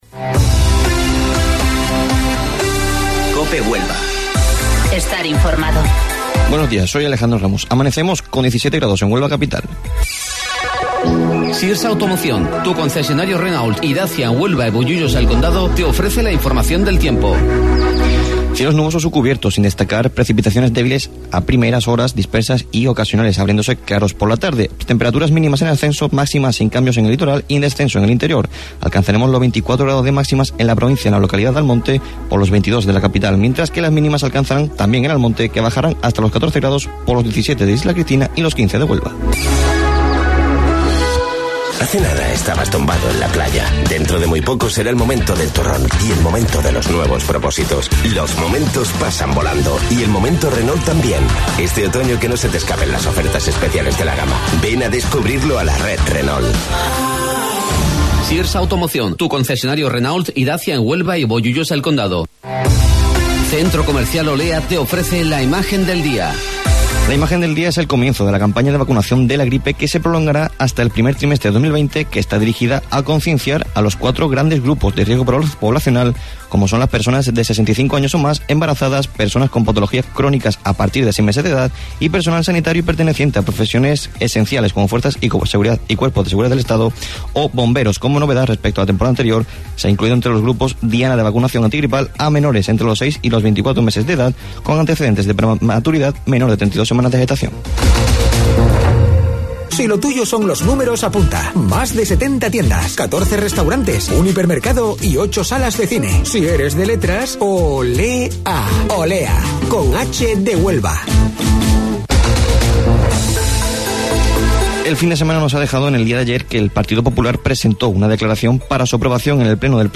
AUDIO: Informativo Local 08:25 del 28 de Octubre